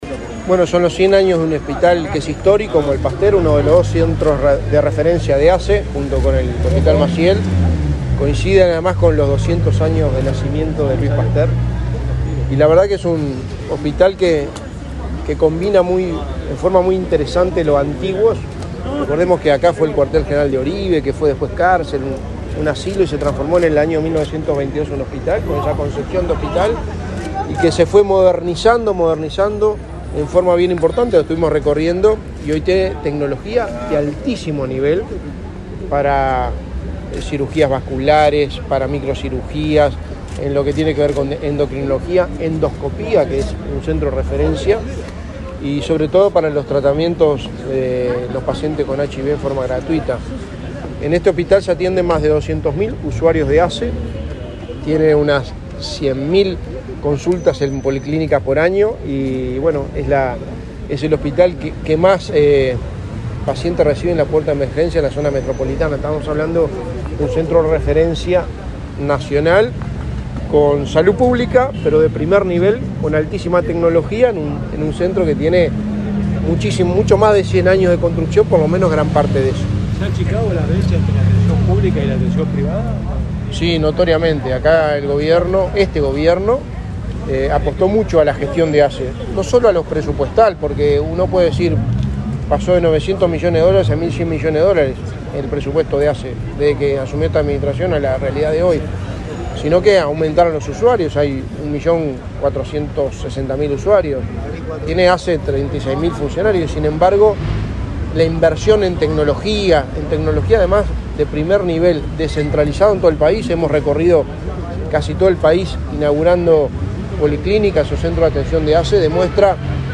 Declaraciones a la prensa del secretario de Presidencia, Álvaro Delgado
El secretario de Presidencia, Álvaro Delgado, participó de la celebración por los 100 años del hospital Pasteur y, luego, dialogó con la prensa.